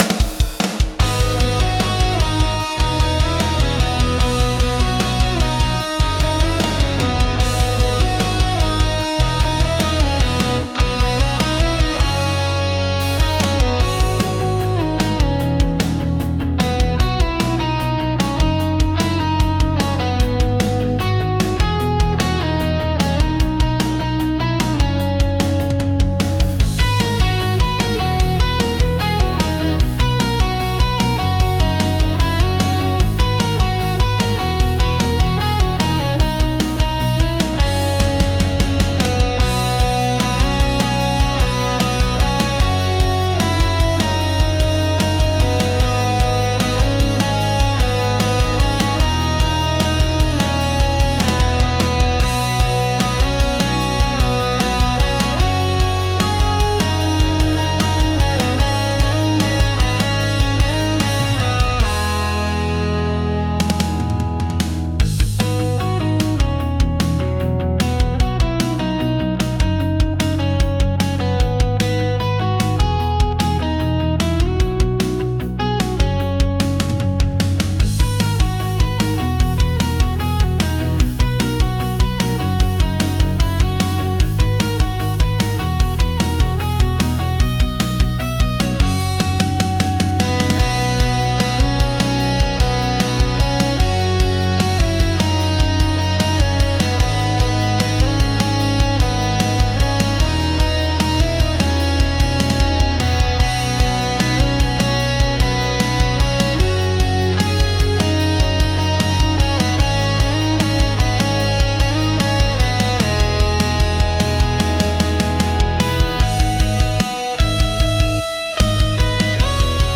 Genre: Pop Punk Mood: Electric Editor's Choice